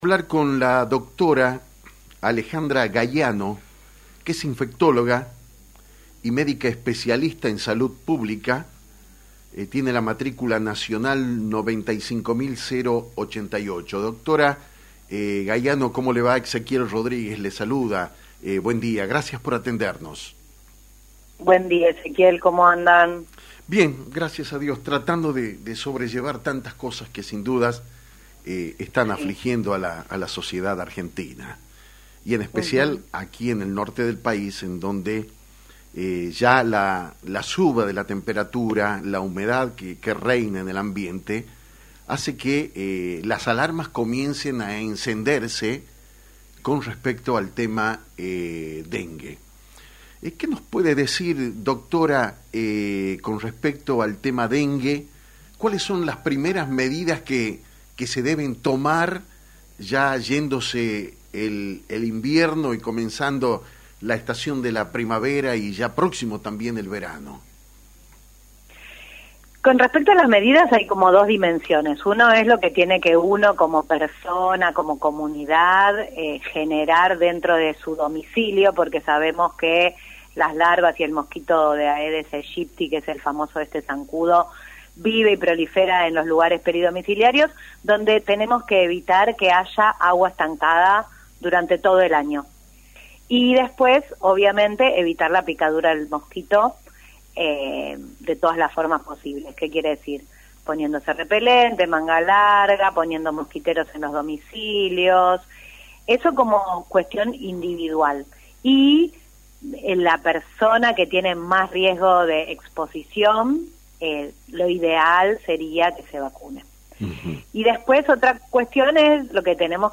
En diálogo exclusivo
Actualidad en Metro, Metro 89.1 mhz, la médica pediatra e infectóloga